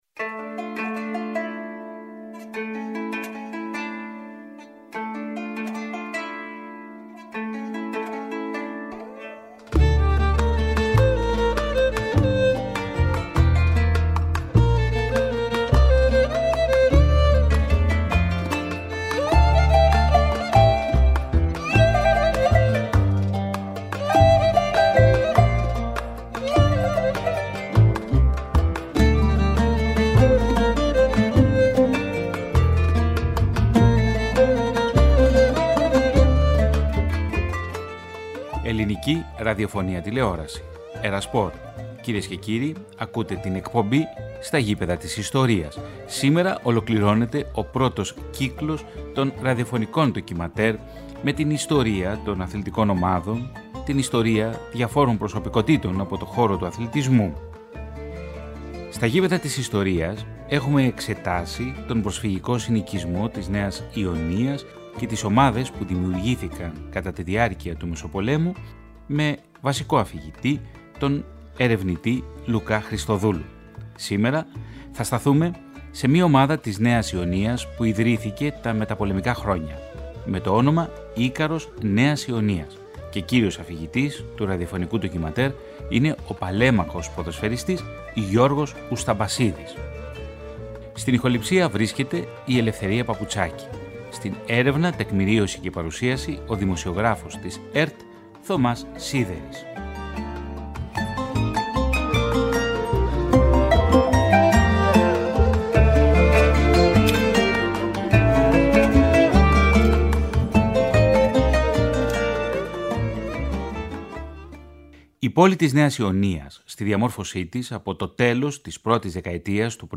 Με τη μετάδοση του 29ου επεισοδίου για την ποδοσφαιρική ομάδα του Ικάρου Νέας Ιωνίας, ολοκληρώνεται ο πρώτος κύκλος της εκπομπής «Στα γήπεδα της ιστορίας», μία εκπομπή που επανέφερε στον αέρα της ΕΡΑ ΣΠΟΡ το αθλητικό ραδιοφωνικό ντοκιμαντέρ.
ΝΤΟΚΙΜΑΝΤΕΡ